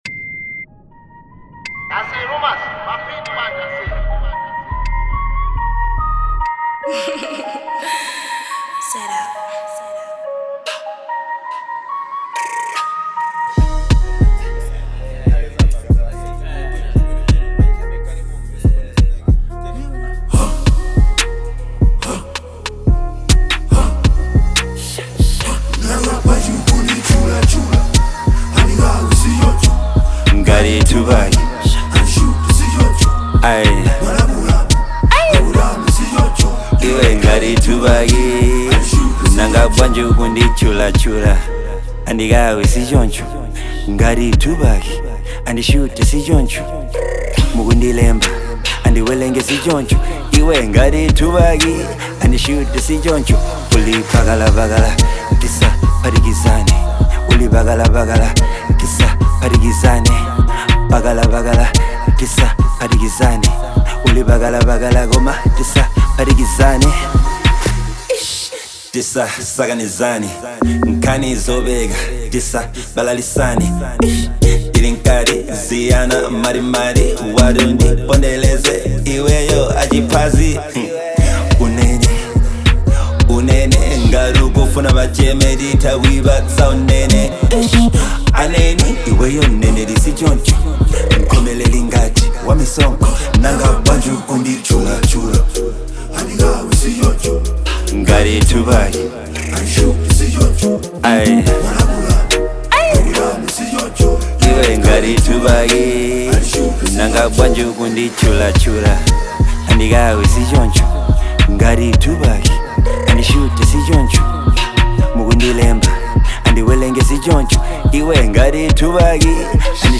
Genre : Drill/Hiphop